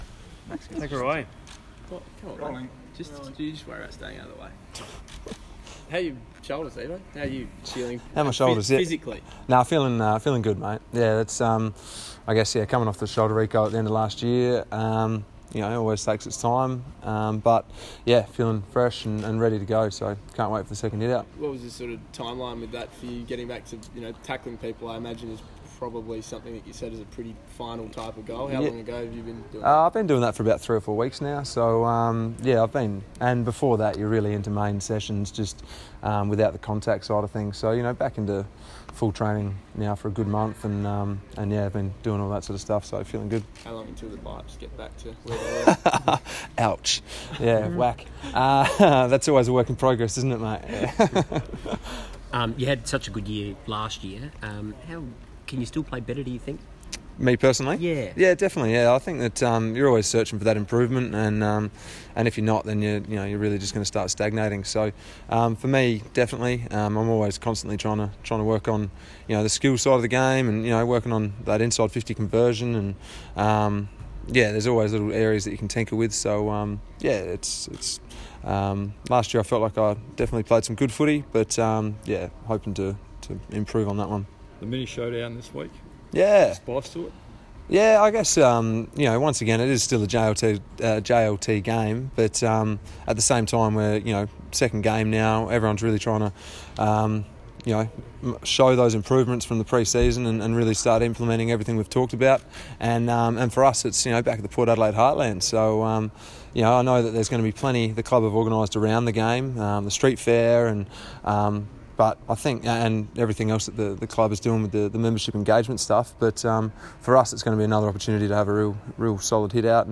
Brad Ebert press conference - Monday, 5 March, 2018
Brad Ebert talks with media as Port Adelaide prepares to host Adelaide in its final JLT Community Series clash, at Alberton on Saturday, 10 March.